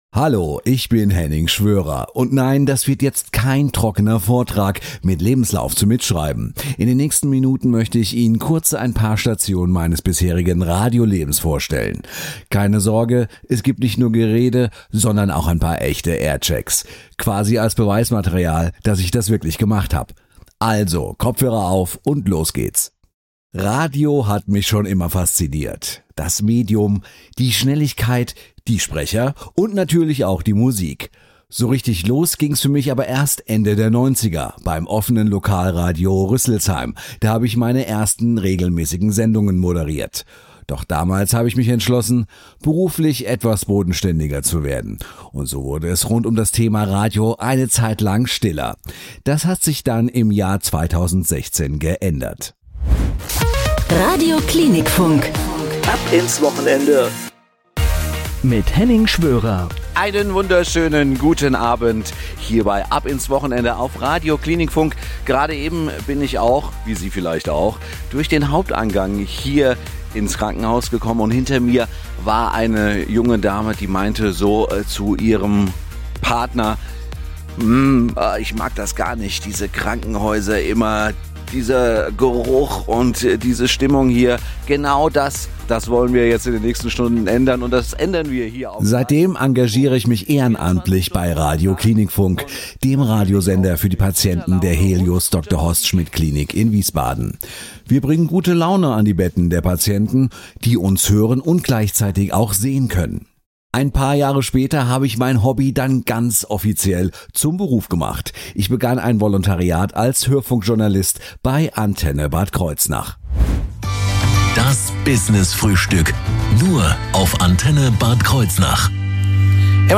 aircheck.mp3